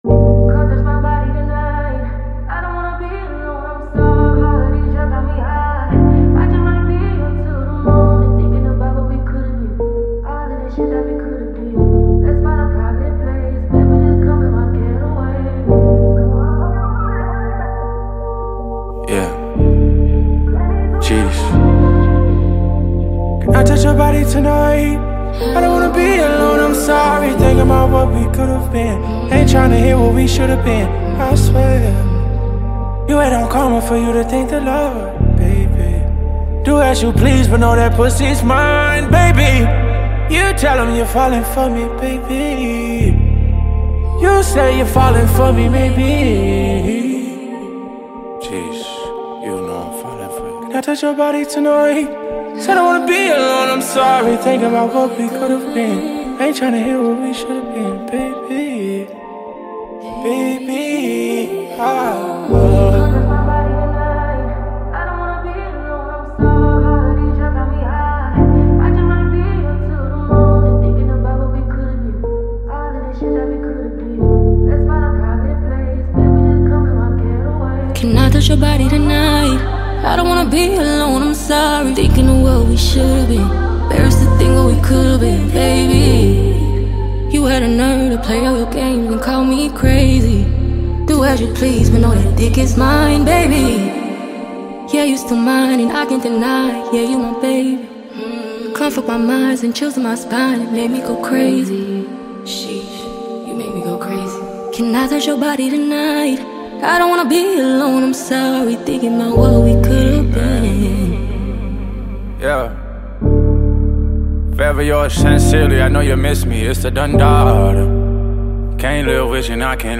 smooth delivery